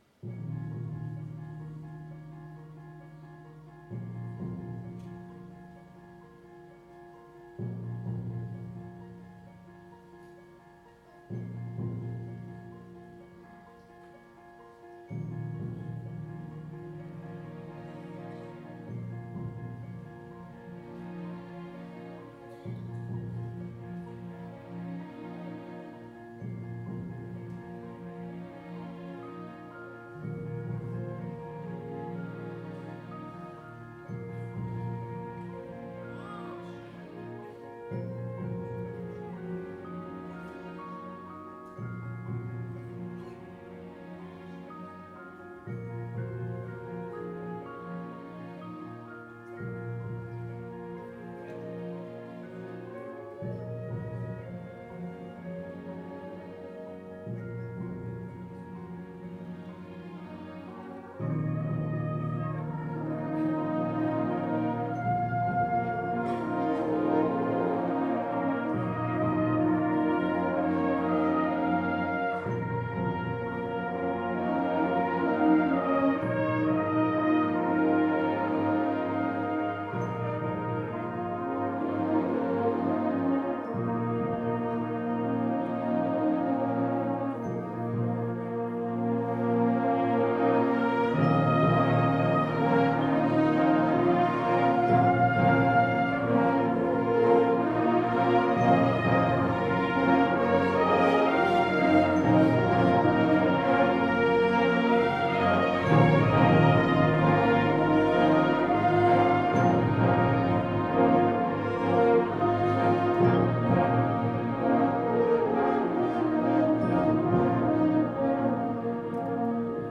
09 1er Morceau Commun Concert Fougeres
09_1er_morceau_commun_concert_Fougeres.mp3